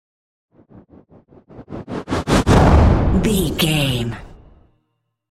Dramatic whoosh to hit trailer
Sound Effects
Atonal
dark
futuristic
intense
tension